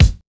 • 00s Clean Kick Single Hit F Key 09.wav
Royality free kick drum sound tuned to the F note. Loudest frequency: 1030Hz
00s-clean-kick-single-hit-f-key-09-ReG.wav